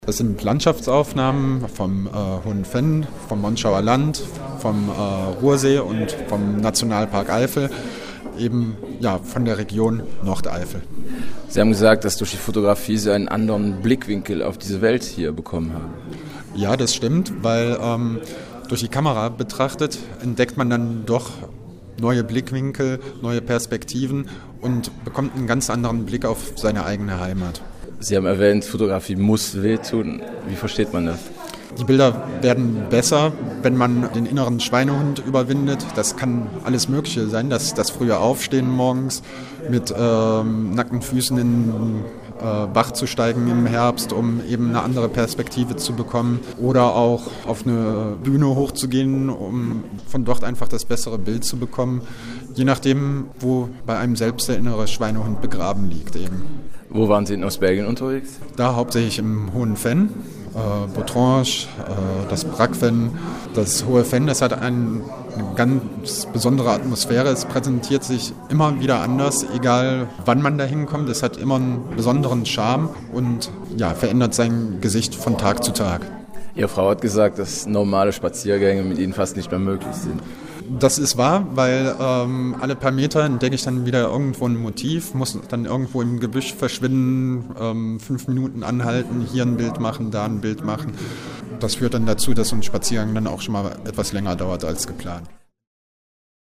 Nach der Buchvorstellung in Monschau hat GE-Redakteur